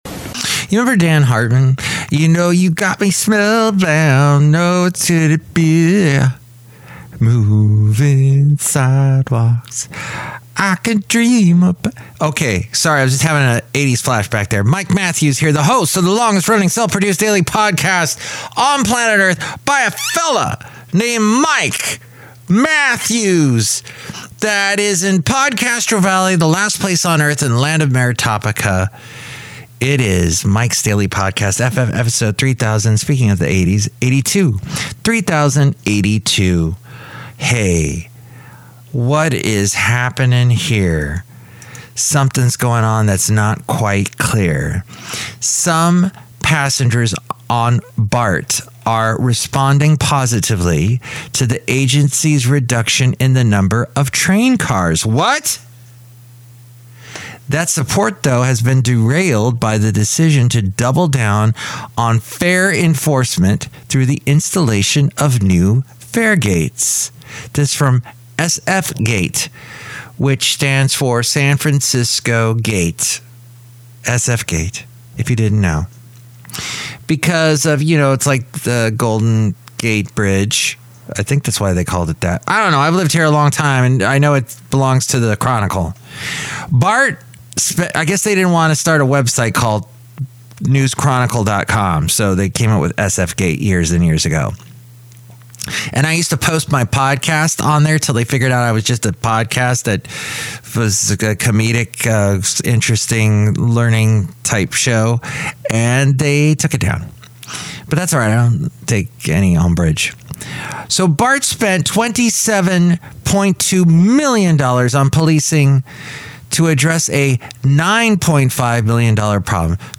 podcasts live from Café Anyway in podCastro Valley